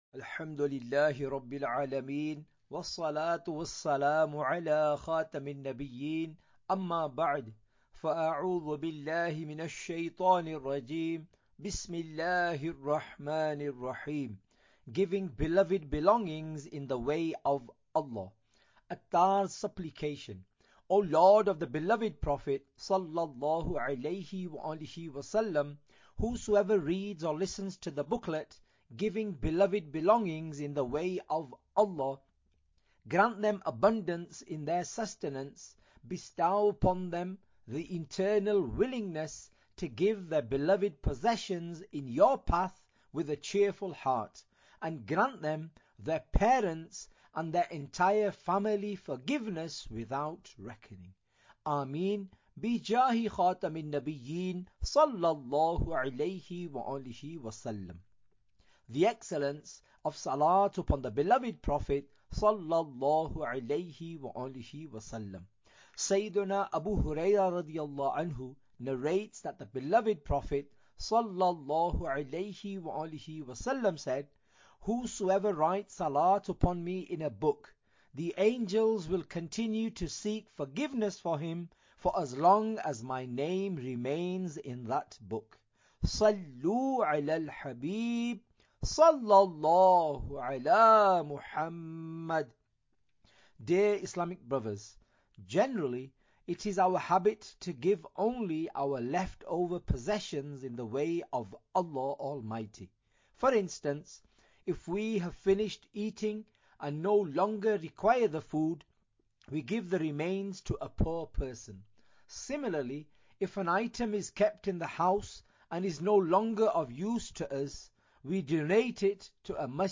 Audiobook - Giving Beloved Belongings in the Way of ALLAH (English)